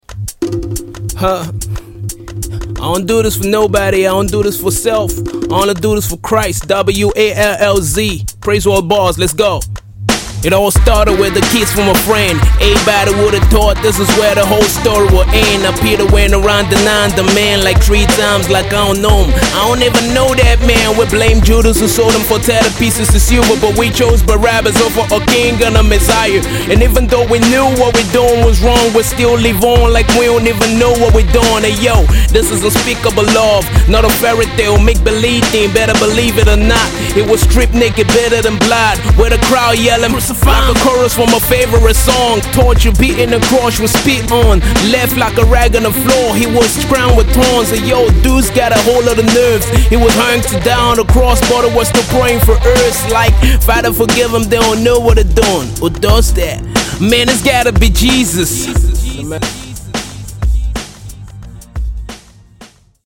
Gospel Hip Hop